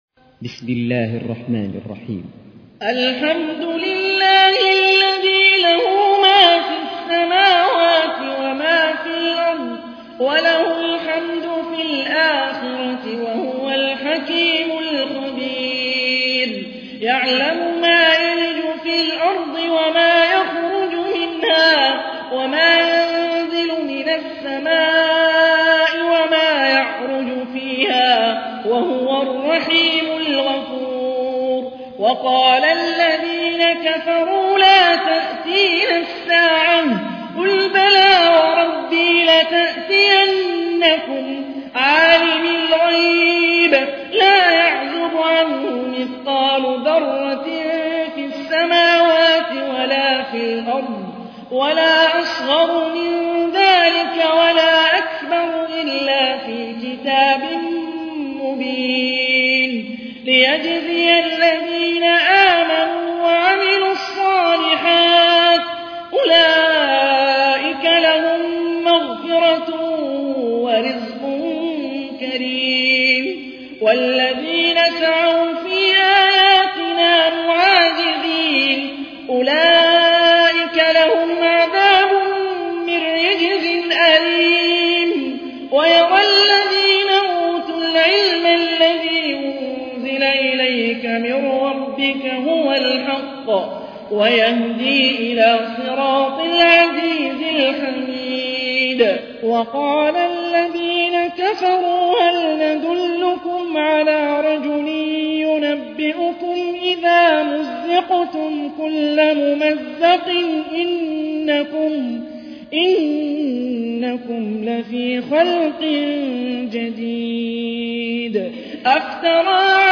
تحميل : 34. سورة سبأ / القارئ هاني الرفاعي / القرآن الكريم / موقع يا حسين